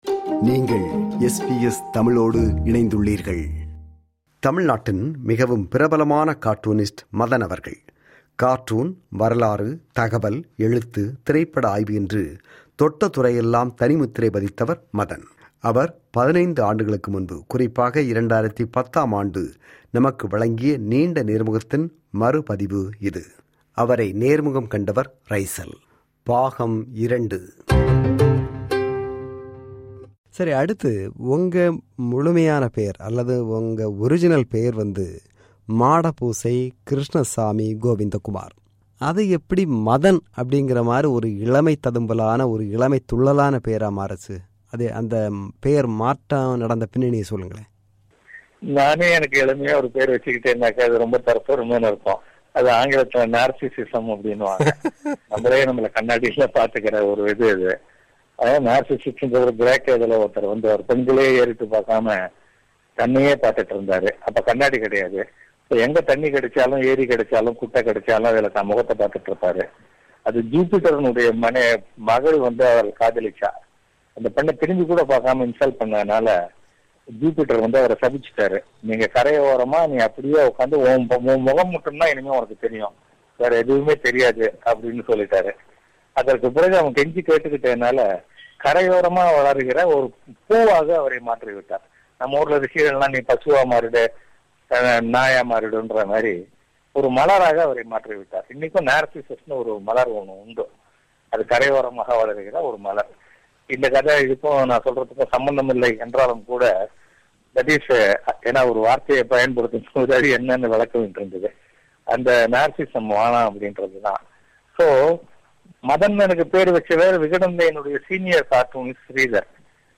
மதன் அவர்கள் 15 ஆண்டுகளுக்கும் முன்பு (2010 ஆம் ஆண்டு) நமக்கு வழங்கிய நீண்ட நேர்முகத்தின் மறு பதிவு இது.